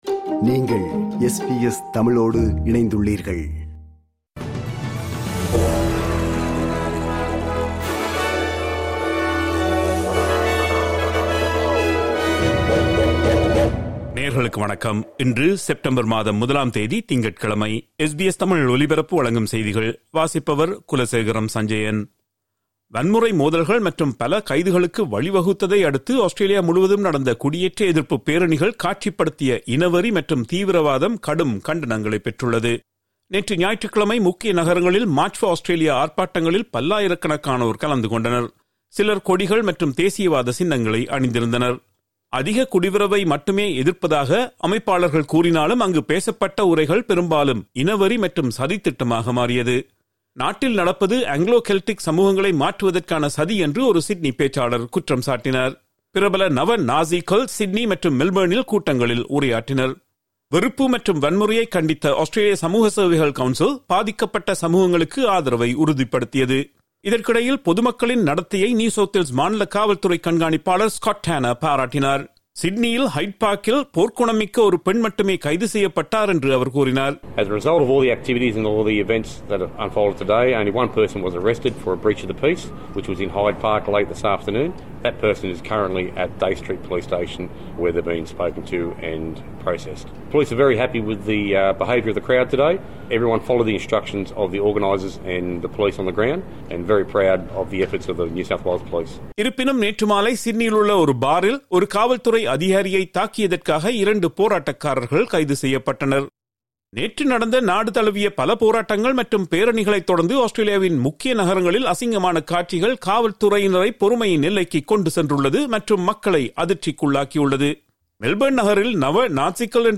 இன்றைய செய்திகள்: 01 செப்டம்பர் 2025 திங்கட்கிழமை
SBS தமிழ் ஒலிபரப்பின் இன்றைய (திங்கட்கிழமை 01/09/2025) செய்திகள்.